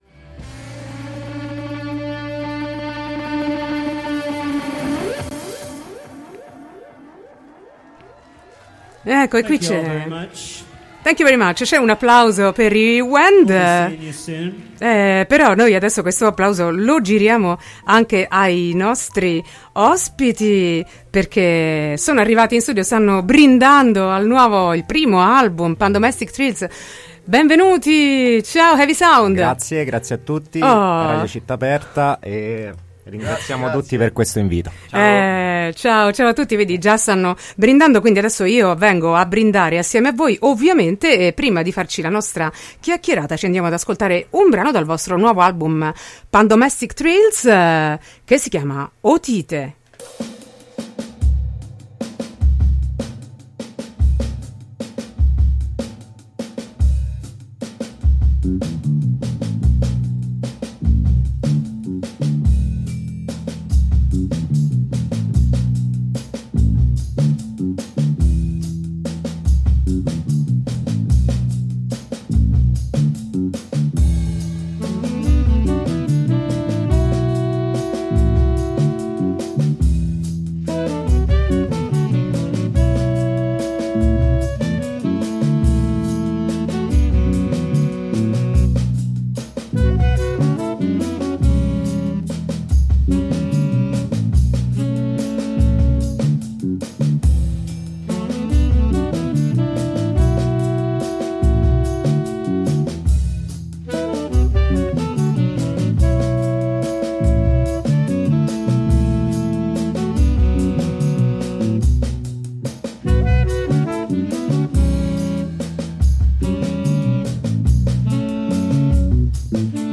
Heavy Sound è musica stratificata di natura collettiva. Solide ritmiche di batteria e percussioni sono base per le melodie aspre e liriche dei sassofoni. Il basso è in mezzo, energico e portante.